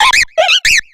infinitefusion-e18/Audio/SE/Cries/AMBIPOM.ogg at 290b6f81d1f6594caaa3c48ce44b519b0dfb49f9